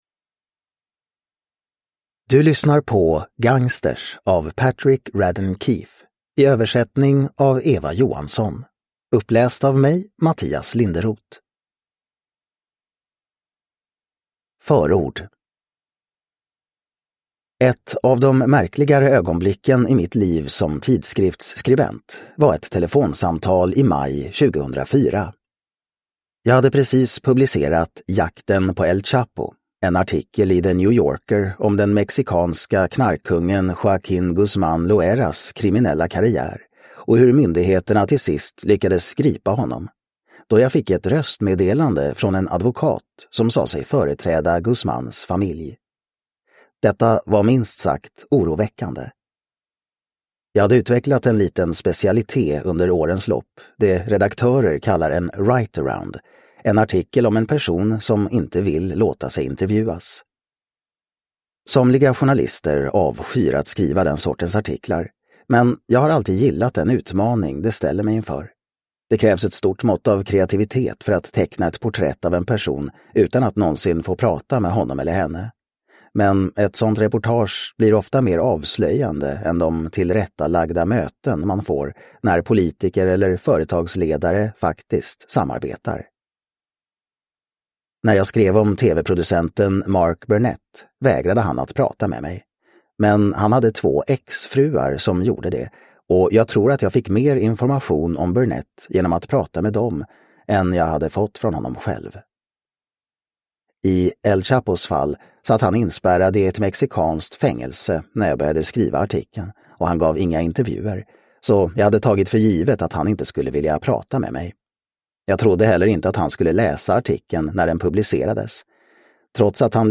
Gangsters : sanna historier om skurkar, svindlare, mördare och rebeller – Ljudbok – Laddas ner